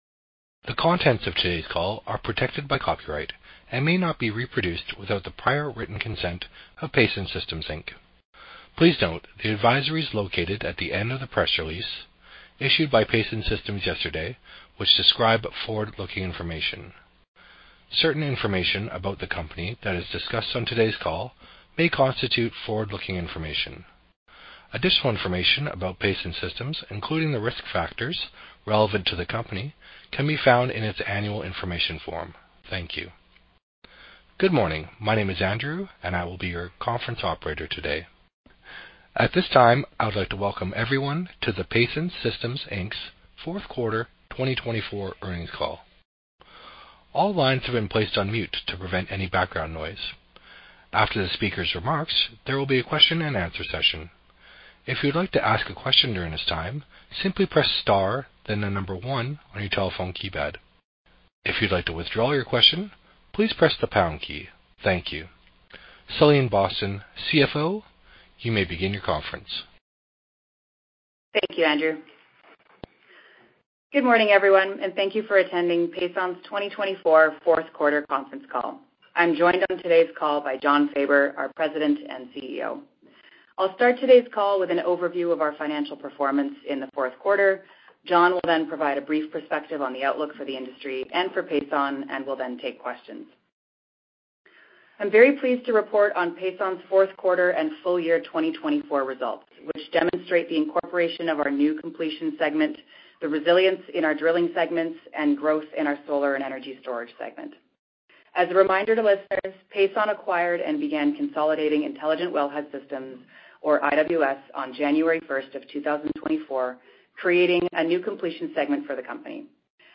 Pason_Conference_Call_Q4_2024.mp3